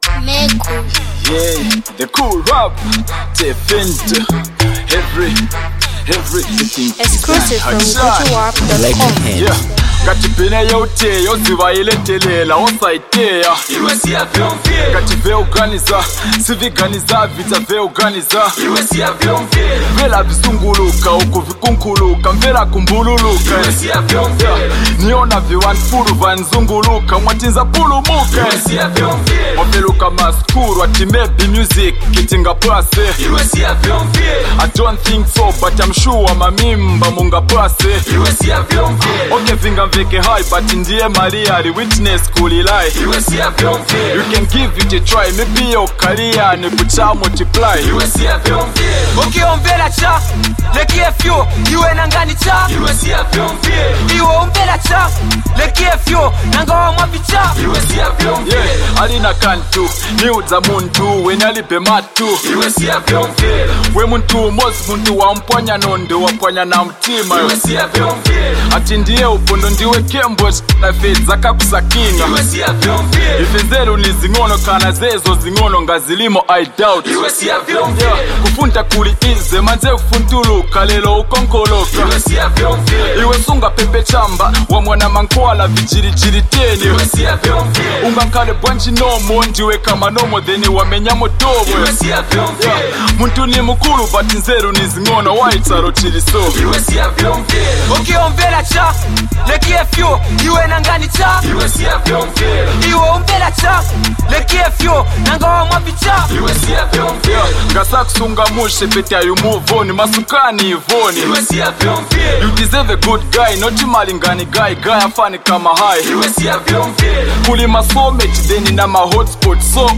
with its catchy beat and smooth flow
hip-hop and rap music